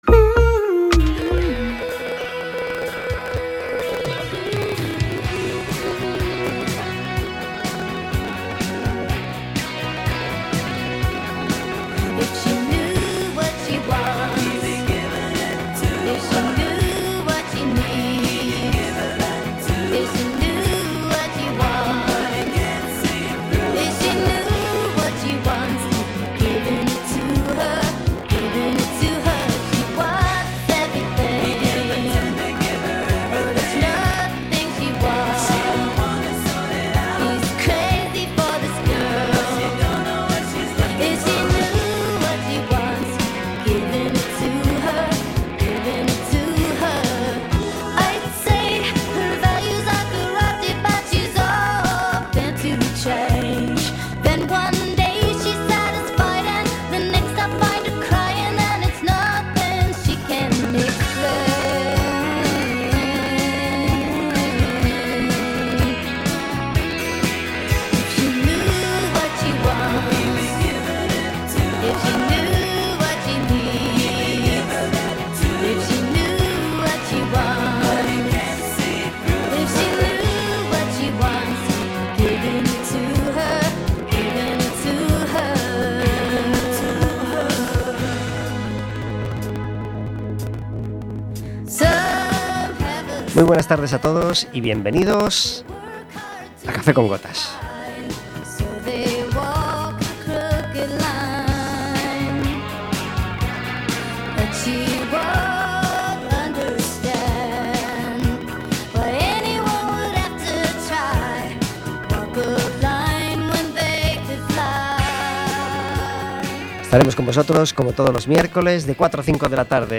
Un invitado cada día